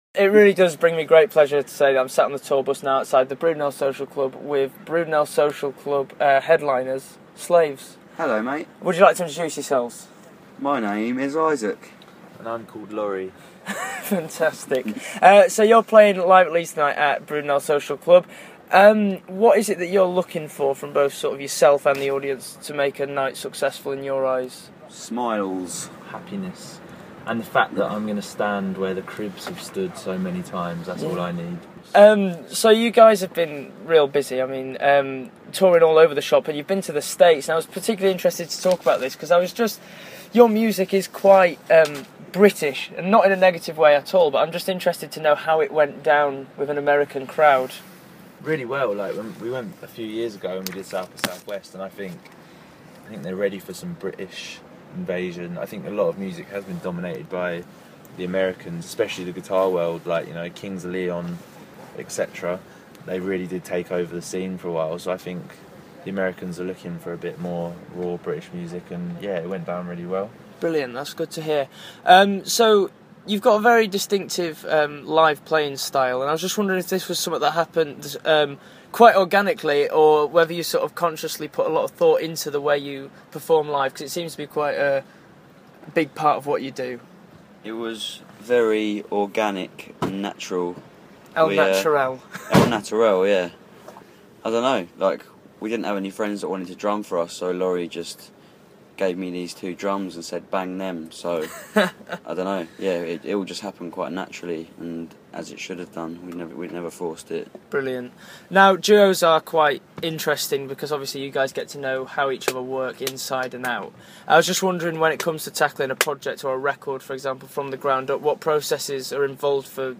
Before their show at the Brudenell Social Club for Live at Leeds.